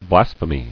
[blas·phe·my]